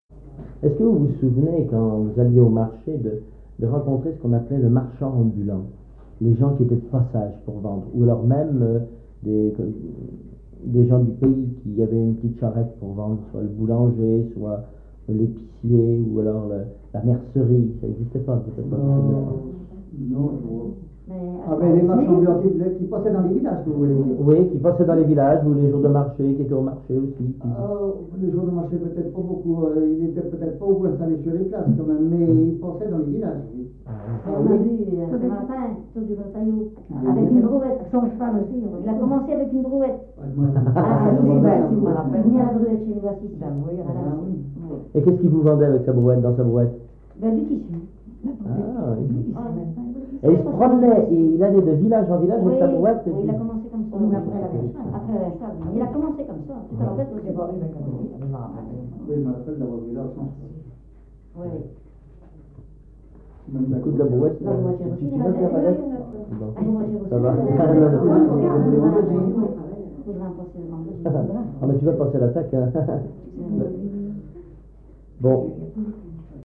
Enquête Puy du Fou, enquêtes des Puyfolais
Témoignage